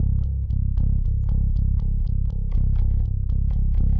Live Bass Guitar Loops " Bass loop 120 bpm rock eights c0
描述：小套的贝斯循环典型的摇滚八音，速度为120 bpm不同的音符（在文件名后面）。循环完美。有压缩器的线型低音信号。指点迷津。
Tag: 低音吉他 手指 摇滚 现场 吉他 120BPM 八分 低音 不断